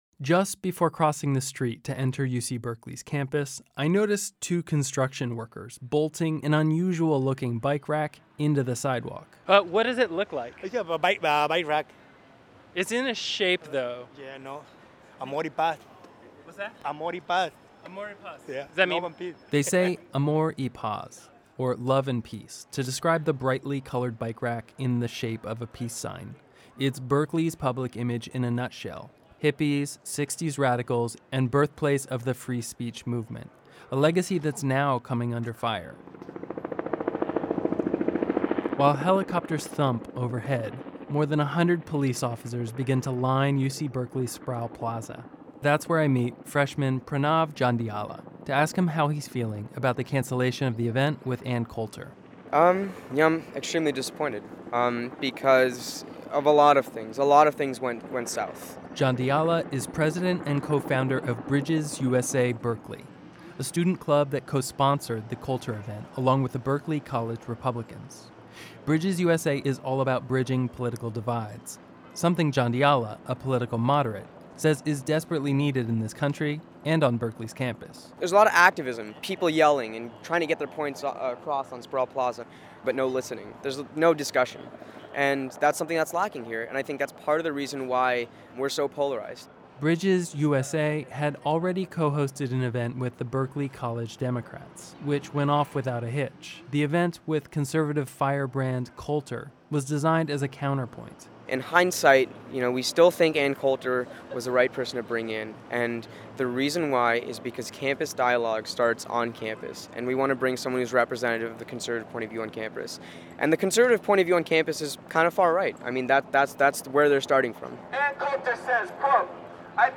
So I spent the day on campus to find out how young people feel UC Berkeley’s iconic past reflects what’s happening today.